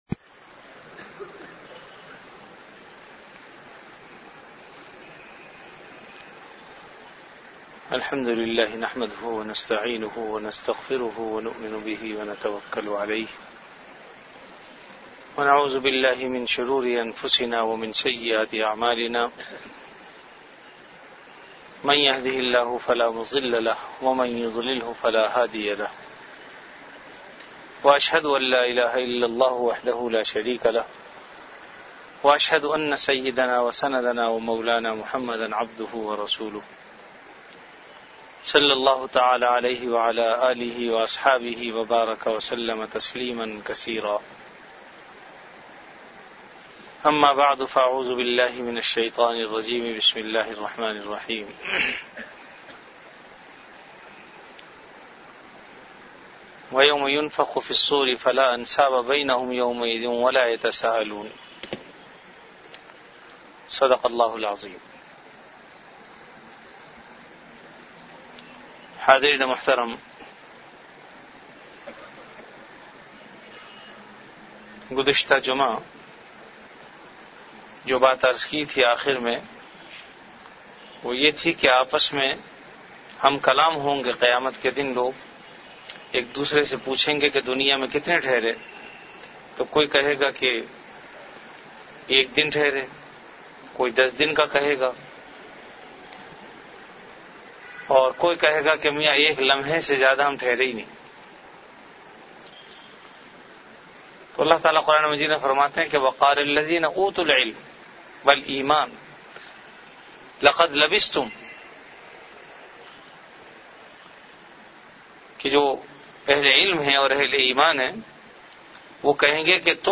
Bayanat · Jamia Masjid Bait-ul-Mukkaram, Karachi
After Isha Prayer